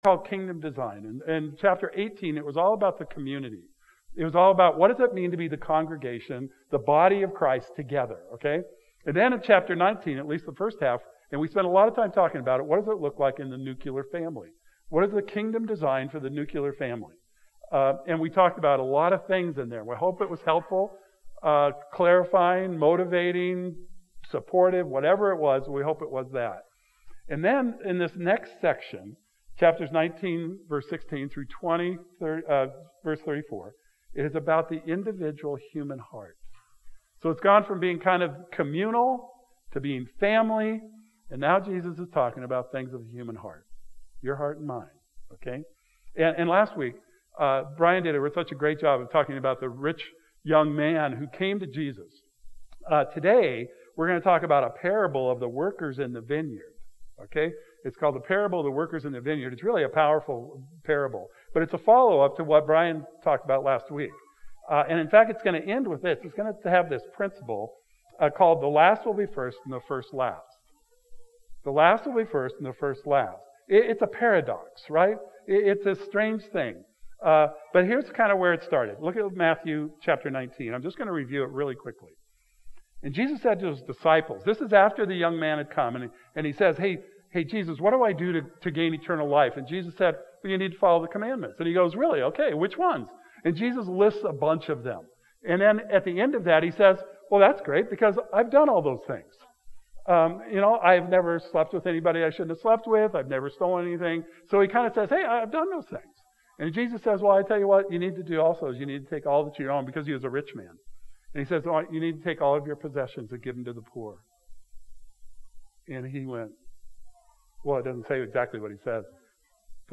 the livestream for the first video unexpectedly quit, so the second half of the sermon can be found here.